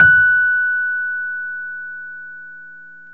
RHODES-F#5.wav